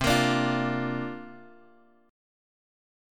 C 9th Suspended 4th